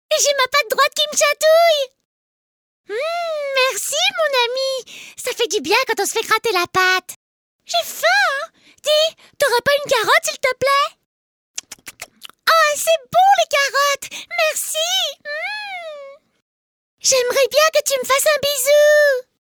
Voix cartoon de la peluche Lapinou Toonies (maquette) |
Cartoon